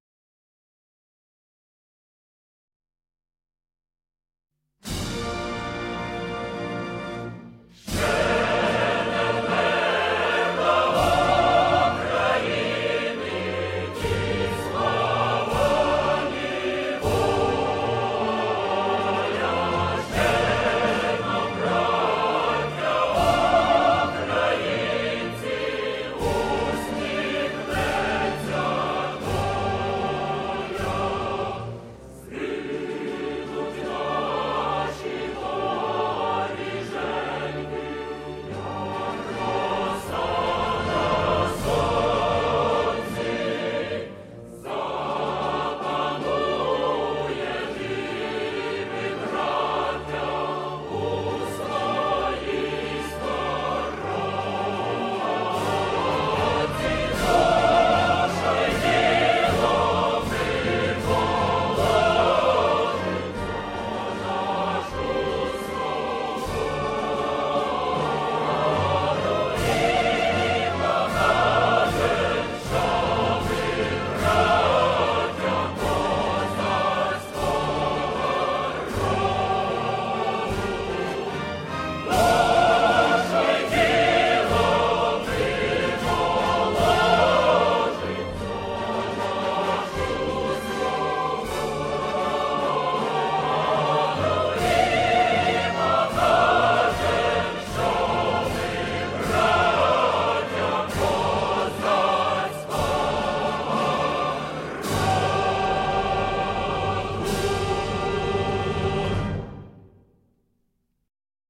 «Ще не вмерла Україна» Виконує хор ім. Верьовки.
Anthem-of-Ukraine_Chorus_Veryovka.ogg